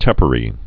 (tĕpə-rē)